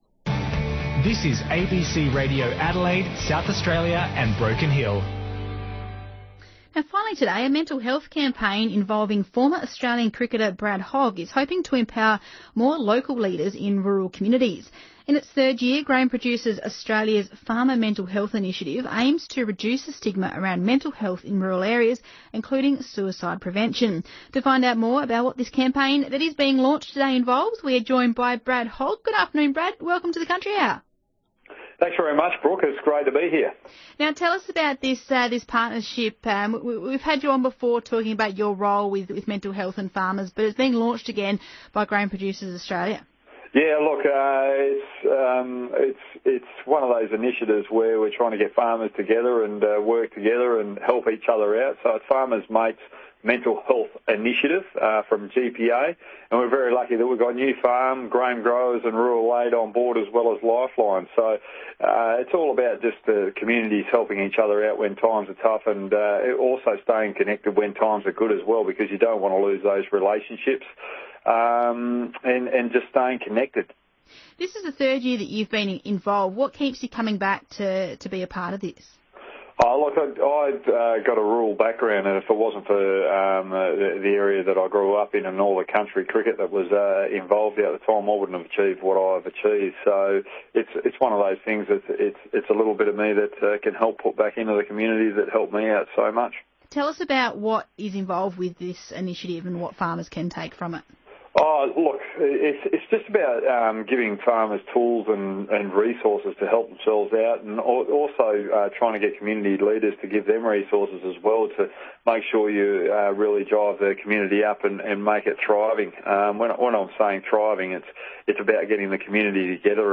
ABC SA Country Hour spoke to Brad about the launch of the program as he prepares to speak about his experiences with mental health and share real life examples from his career as an elite Australian cricketer.